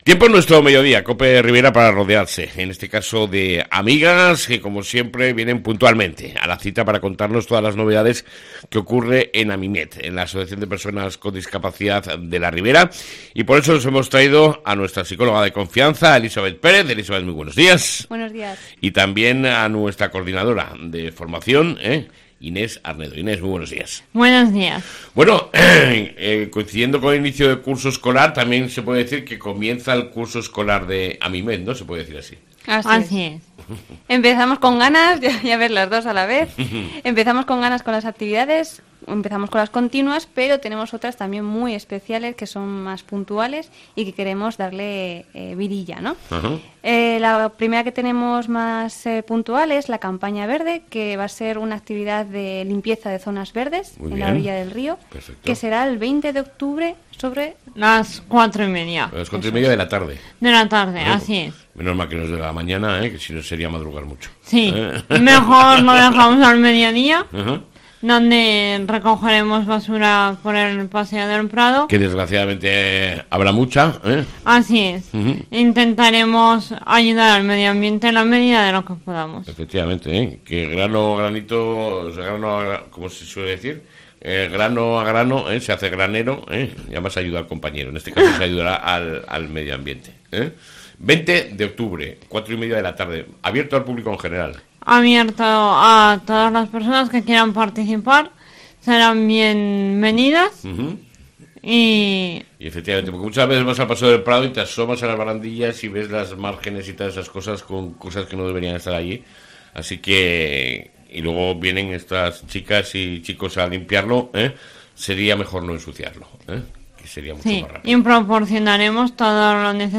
ENTREVISTA CON AMIMET